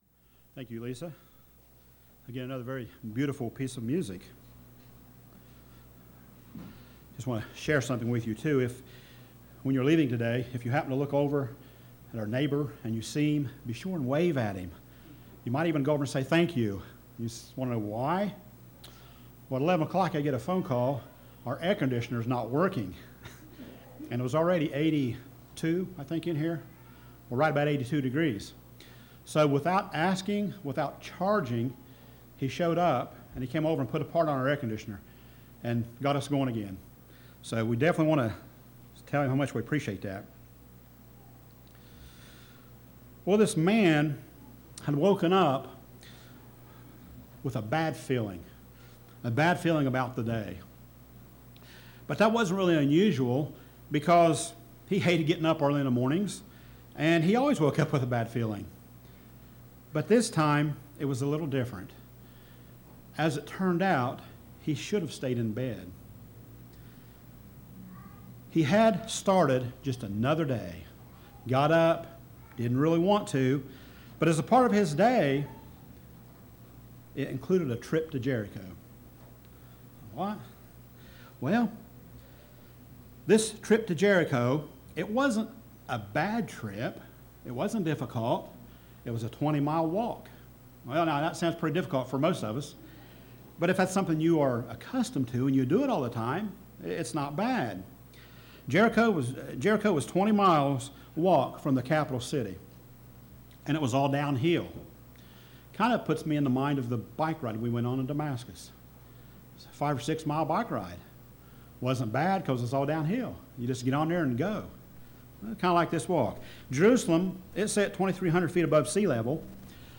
Sermons
Given in Portsmouth, OH